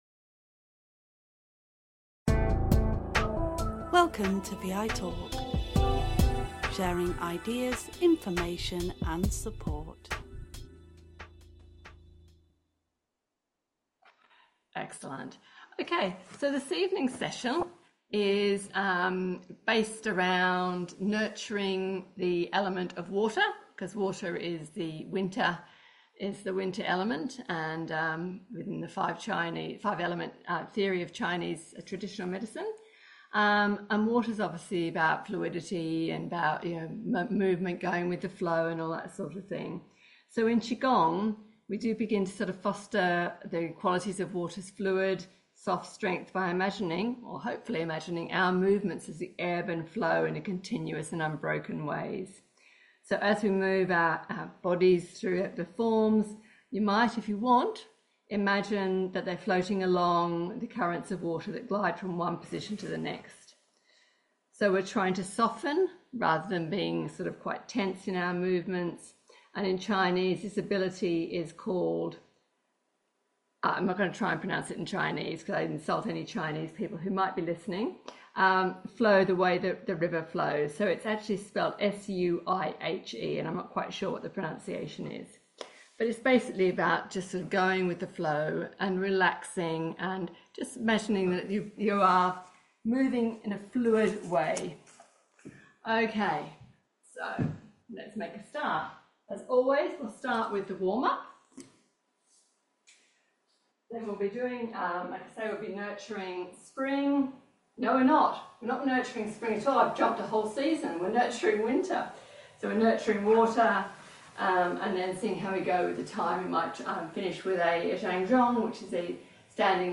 QiGong session